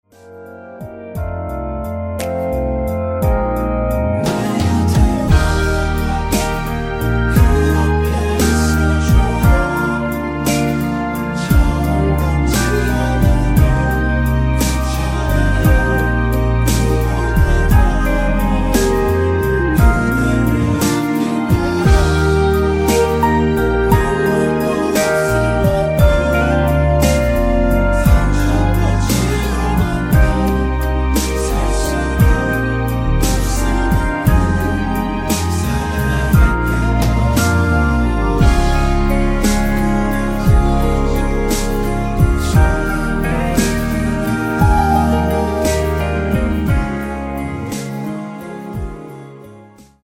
엔딩이 페이드 아웃이라 엔딩을 만들어 놓았습니다.
(-1) 내린 코러스 포함된 버젼 입니다.(미리듣기 참조)
Eb
◈ 곡명 옆 (-1)은 반음 내림, (+1)은 반음 올림 입니다.
앞부분30초, 뒷부분30초씩 편집해서 올려 드리고 있습니다.
중간에 음이 끈어지고 다시 나오는 이유는